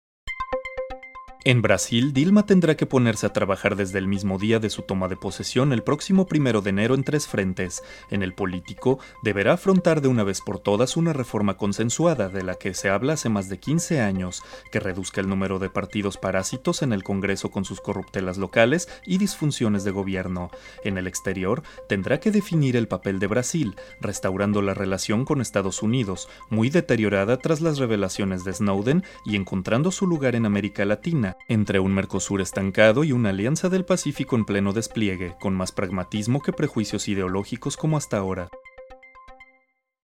• 3Spanish Male No.3
News Anchor